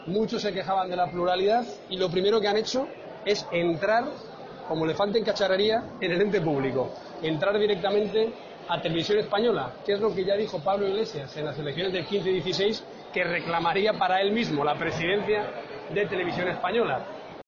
"Quiero un PP en el que quepa todo el mundo, incluso los que no estén en el partido; independientes, talento de fuera para volver a ser esa casa común de lo que hace falta hacer en España", ha afirmado a los periodistas tras mantener una reunión con afiliados en Almería.